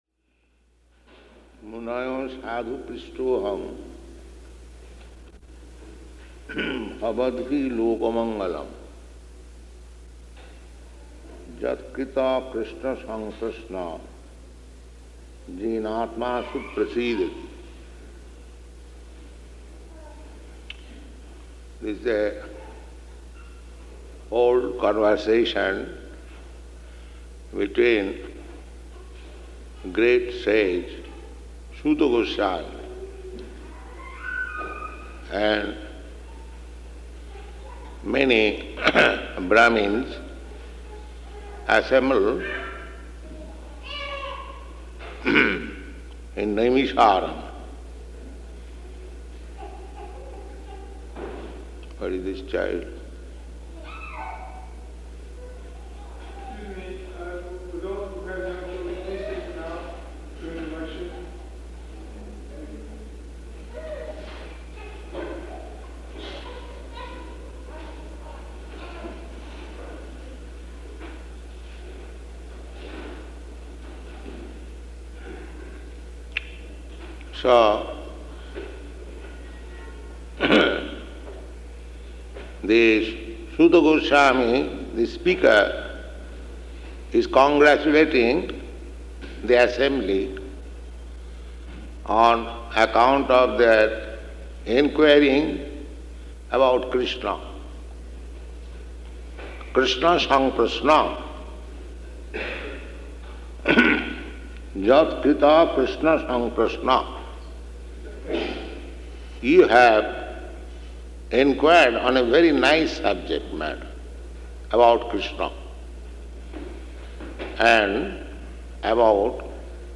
Type: Srimad-Bhagavatam
Location: Montreal
[noise of children] [aside:] What is this child?